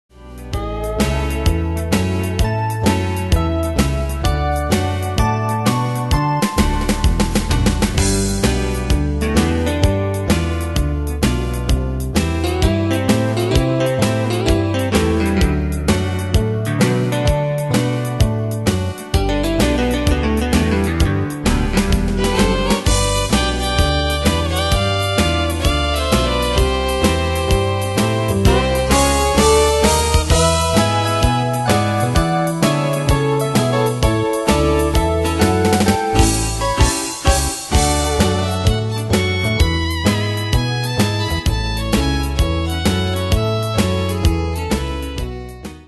Style: Country Année/Year: 1994 Tempo: 129 Durée/Time: 3.41
Danse/Dance: TwoSteps Cat Id.
Pro Backing Tracks